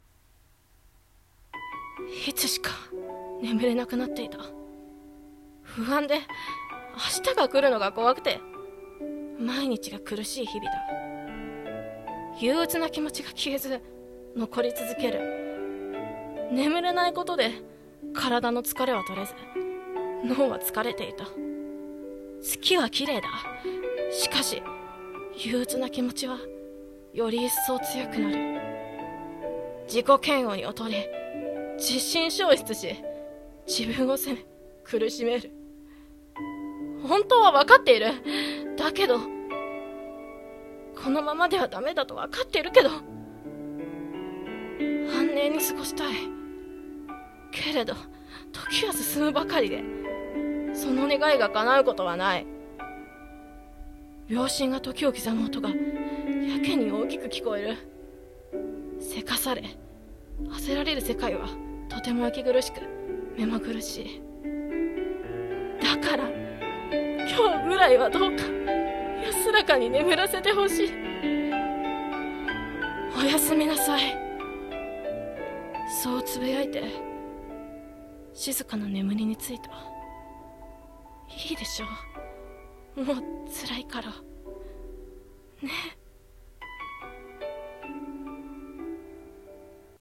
声劇｢眠れない夜